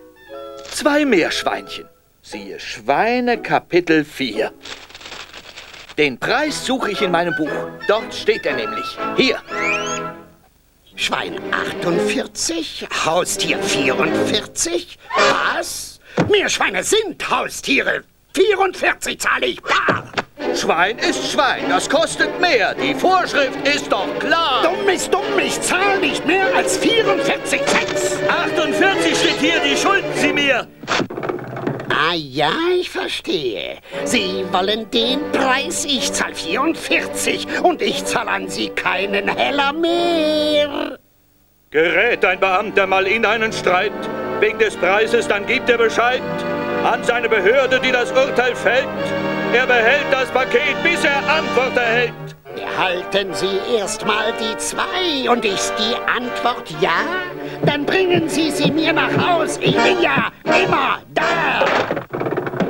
Flannery im Streitgespräch mit McMoorehouse